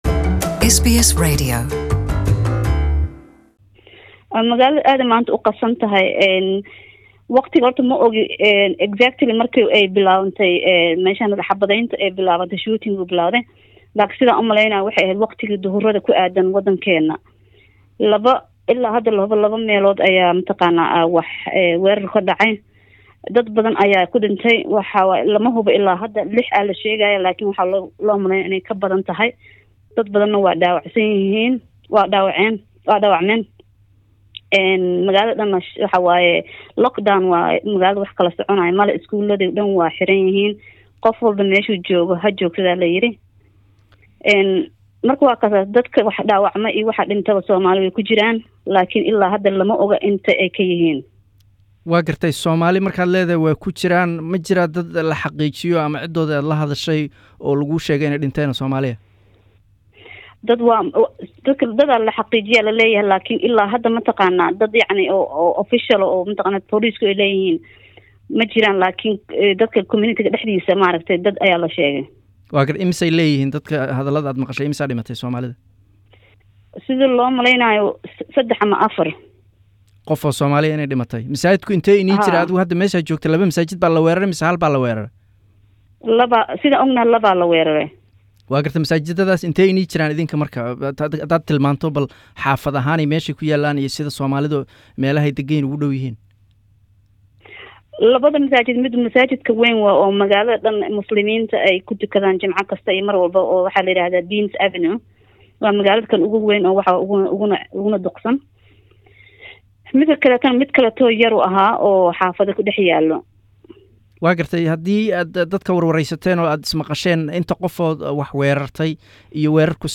Witness interview in Chrischurch